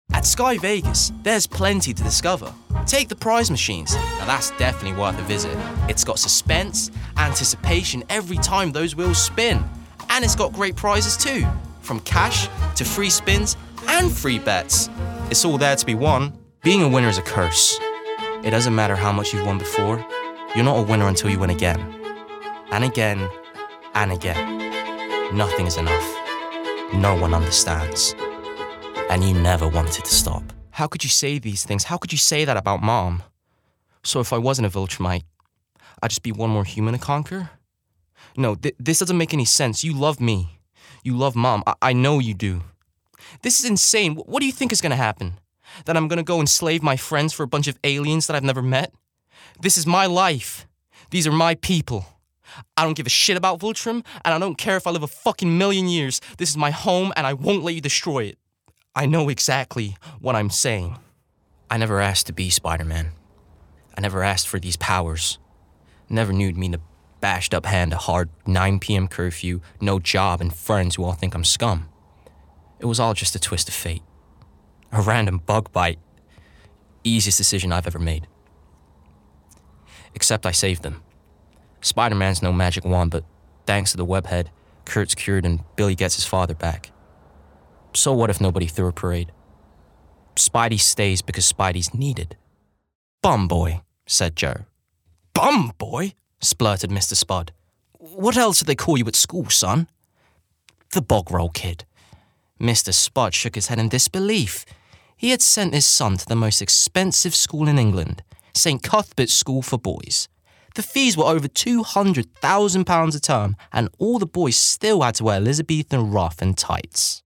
Estuary English
Voicereel:
BRITISH ISLES: Contemporary RP, Heightened RP, London, Liverpool, Multicultural London English (MLE)
GLOBAL: Standard-American, East Coast American, West Coast American, American-Southern States, New York, AAVE
Tenor